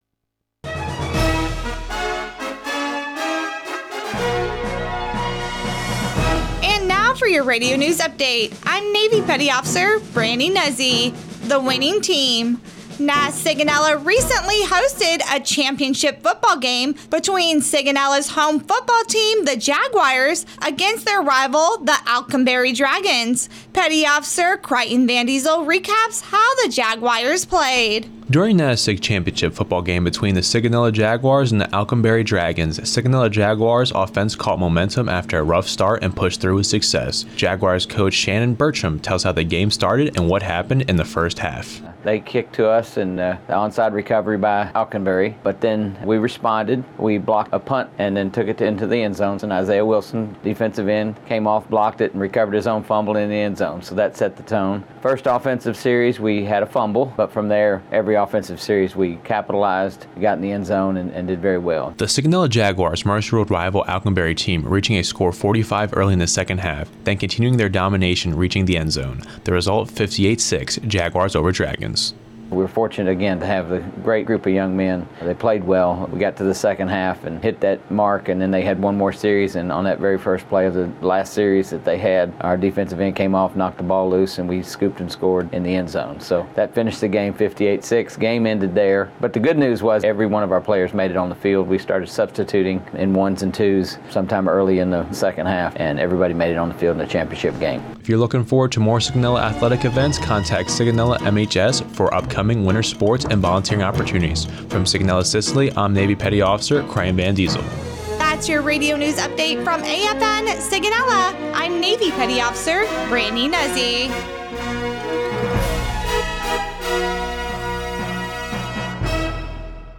NAVAL AIR STATION SIGONELLA, Italy (Oct. 30, 2024) Radio news highlights Naval Air Station (NAS) Sigonella's recent championship football game.